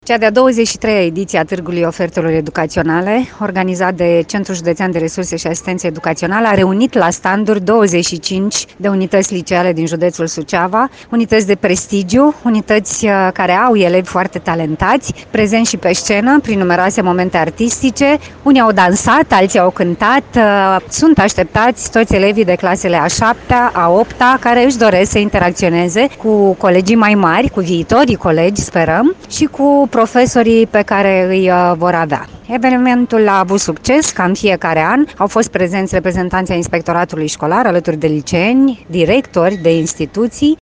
Centrul Judeţean de Resurse şi Asistenţă Educaţională Suceava a organizat astăzi, la Shopping City, Târgul Ofertelor Educaționale.